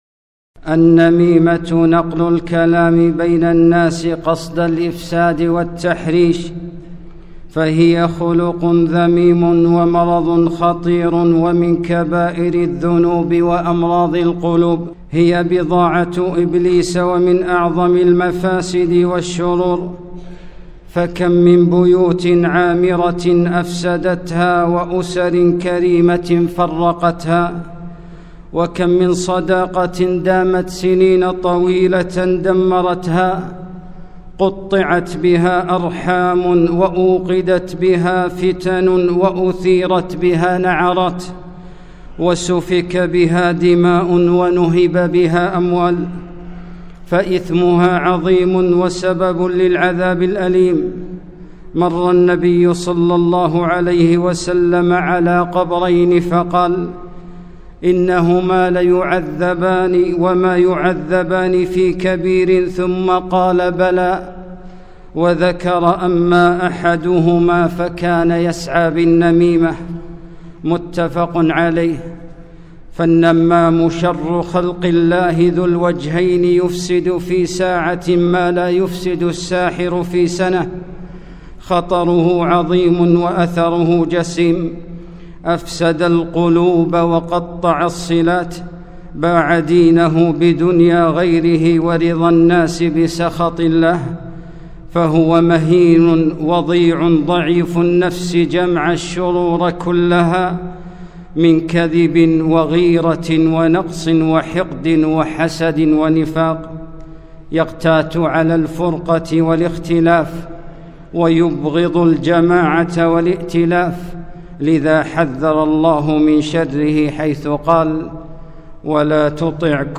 خطبة - لا يدخل الجنة قتات